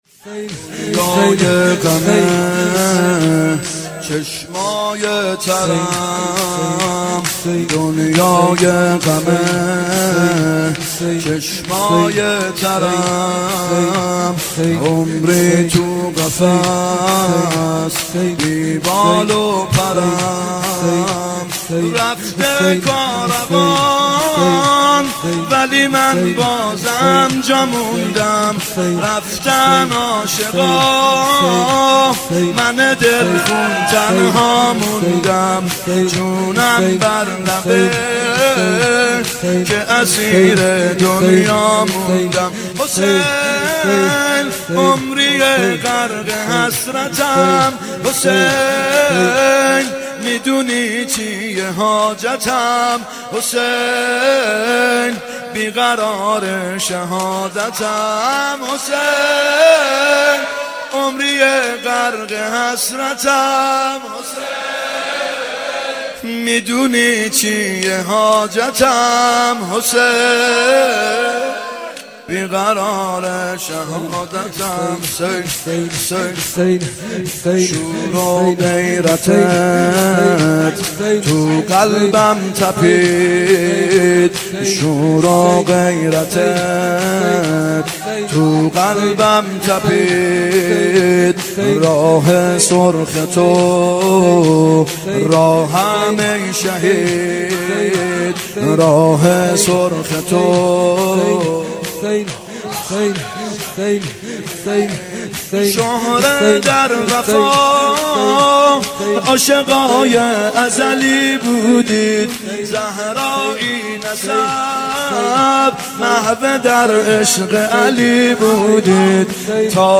محرم 91 شب سوم شور (دنیای غمه چشمای ترم
محرم 91 ( هیأت یامهدی عج)